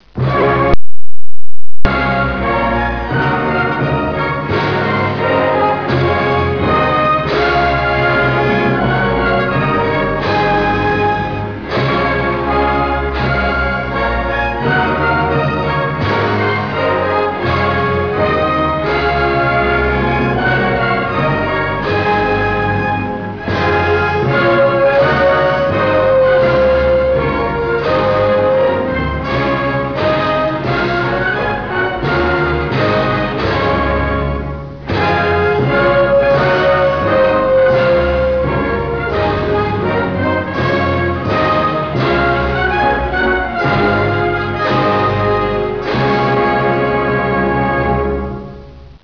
As far as I know it doesn't have words, so I just sing shit like "pah pah PAH pah paRUMParumpa humpadumpa."
But still, it's stirring music. They call it a hymn but somehow it gets my blood flowing.